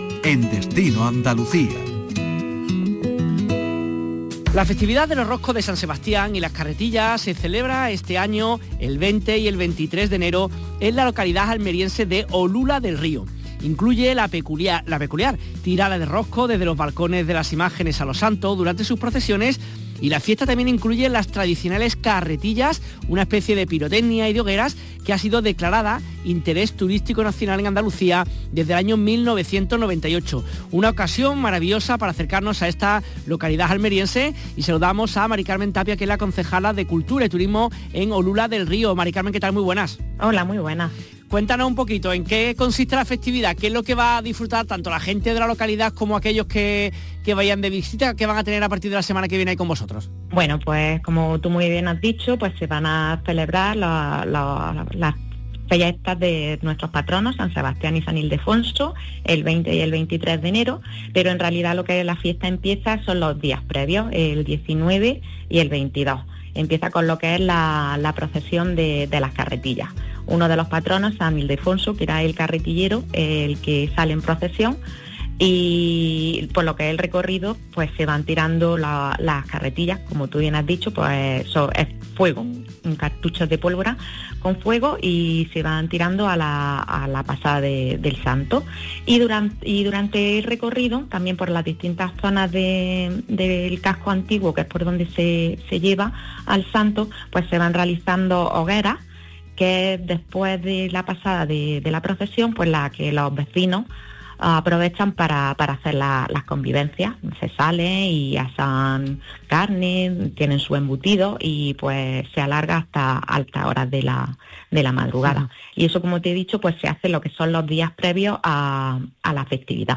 Corte del programa dedicado a Olula del Río: